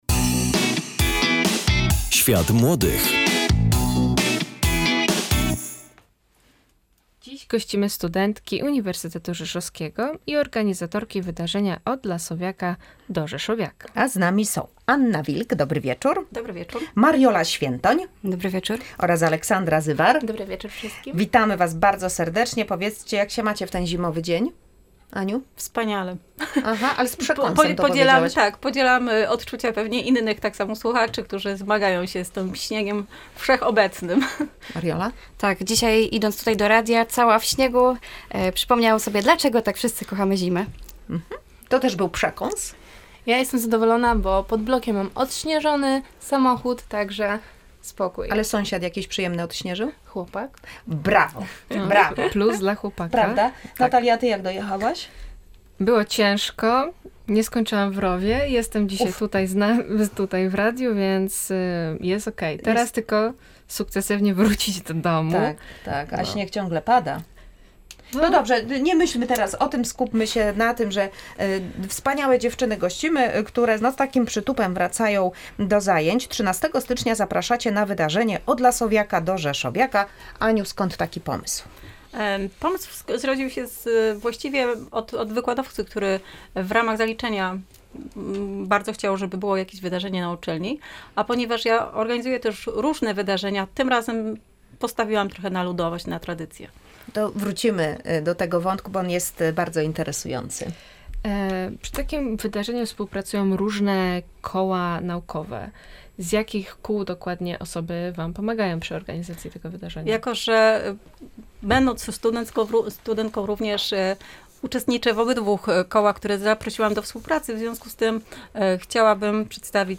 W „Świecie Młodych” odwiedziły nas studentki Uniwersytetu Rzeszowskiego